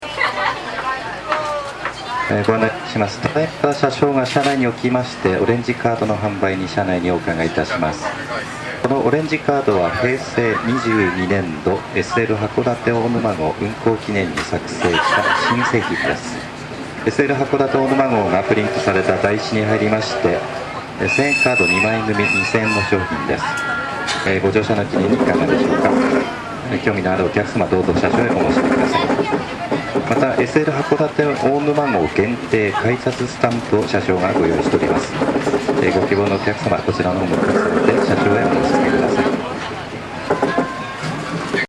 オレンジカードの販売放送